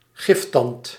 Ääntäminen
IPA: [kʁɔ.ʃɛ]